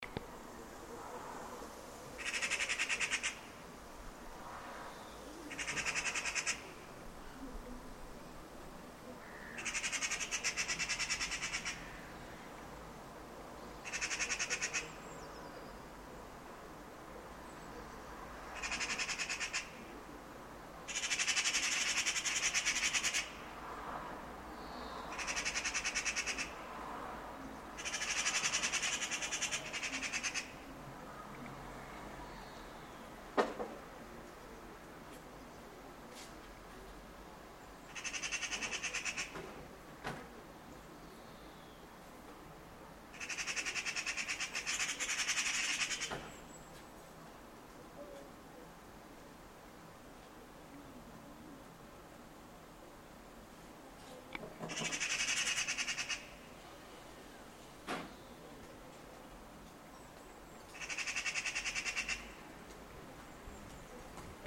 Two magpies calling
Recorded on our balcony in the afternoon